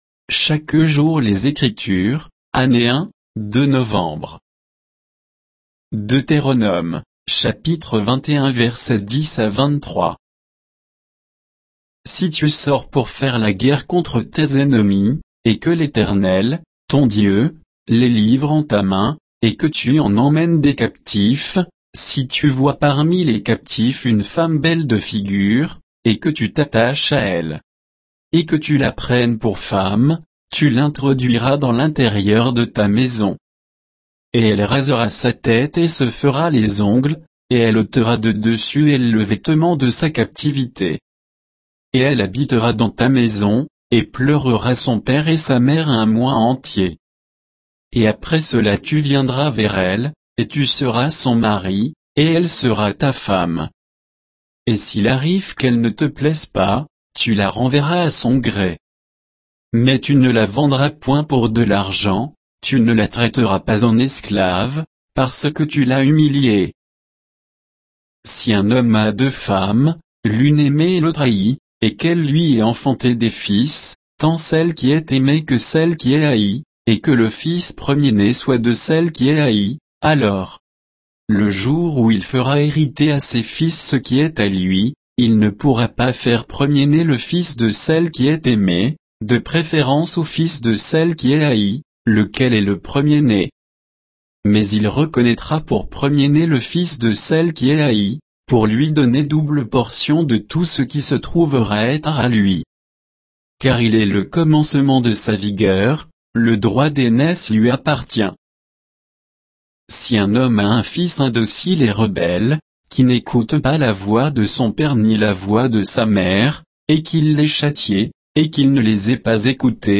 Méditation quoditienne de Chaque jour les Écritures sur Deutéronome 21